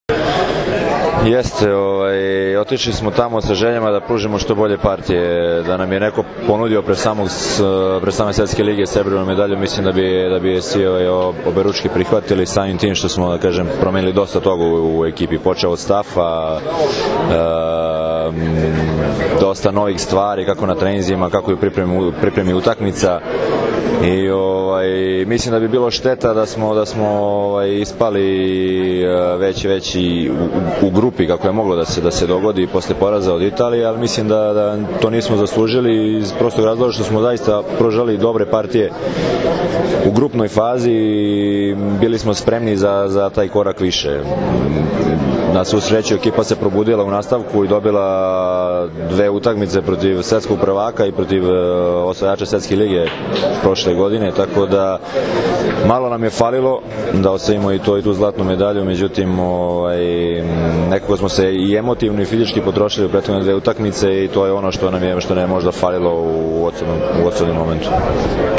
IZJAVA DRAGANA STANKOVIĆA